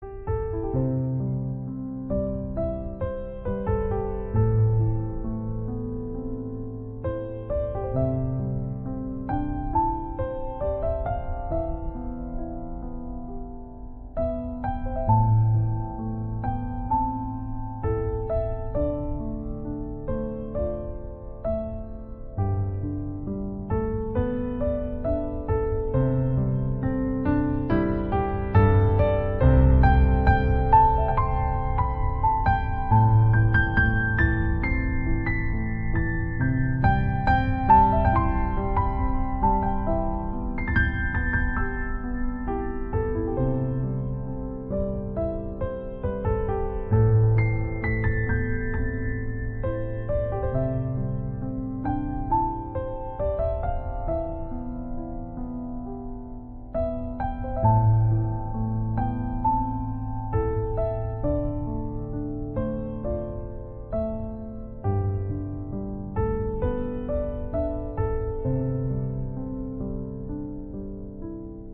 Sức hấp dẫn của bản phối Piano